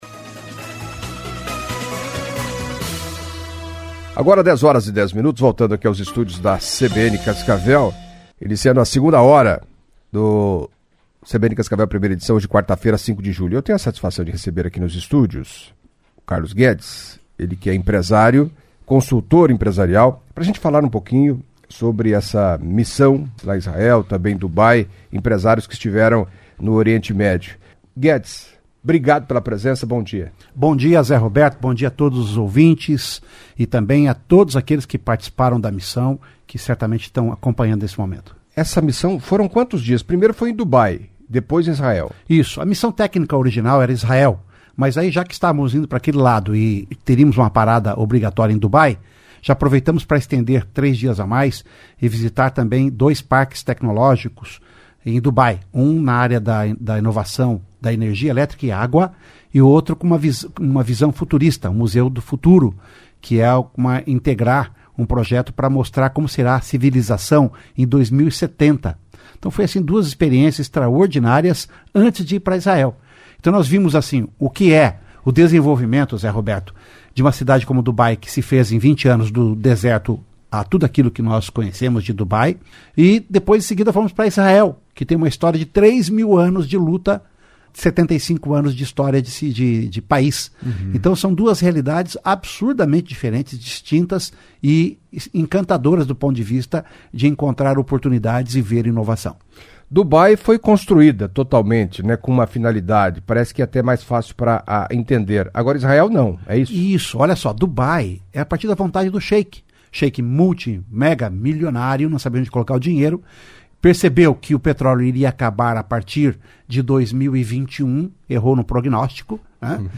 Em entrevista à CBN Cascavel nesta quarta-feira (05)